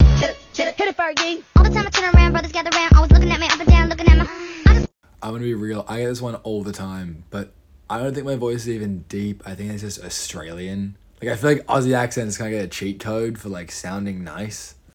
rambling australian